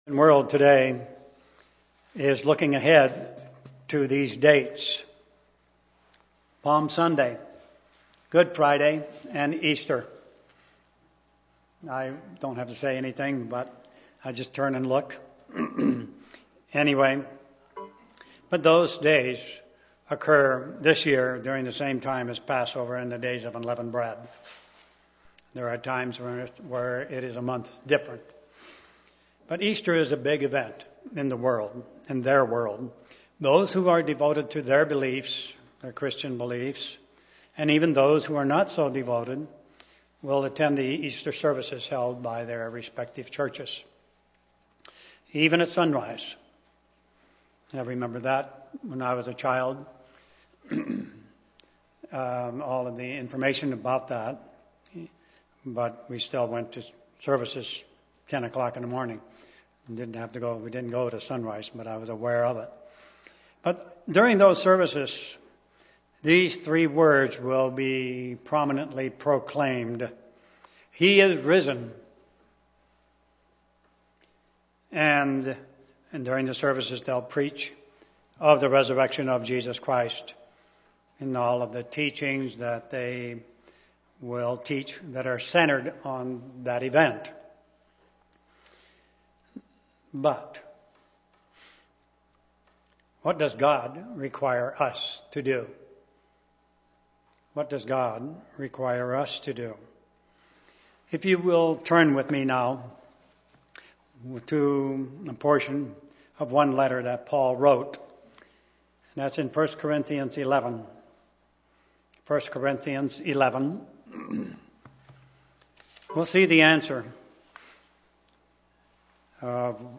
Sermons
Given in Olympia, WA